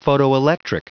Prononciation du mot photoelectric en anglais (fichier audio)
photoelectric.wav